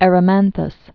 (ĕrə-mănthəs, -thŏs, ĕ-rēmän-thôs)